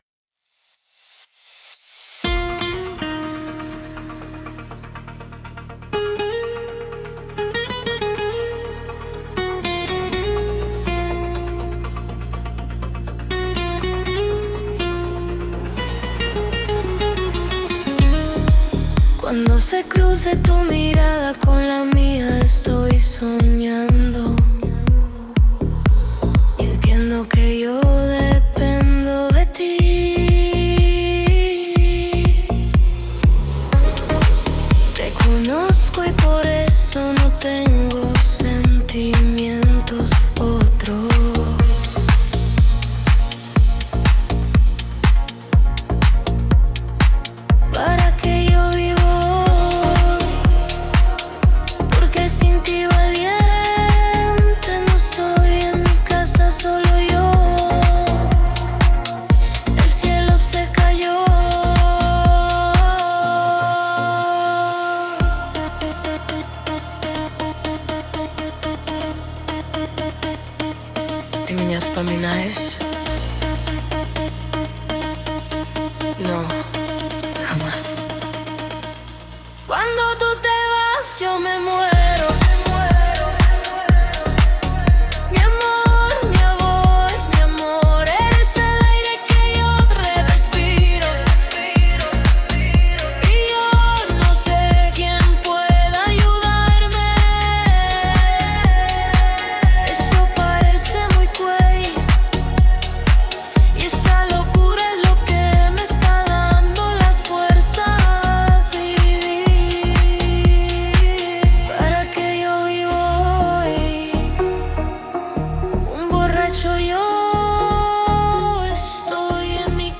mp3 0:00 текст мой, музыка ИИ Скачать